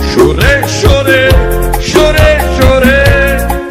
Category: Reactions Soundboard